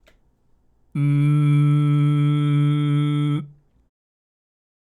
音色のイメージは、自分よりも10mぐらい背の高い巨人になったつもりで、くぐもった深い声を作れたらOKです。
※喉頭を下げたグーの声(ん)
野太い声が出ますね！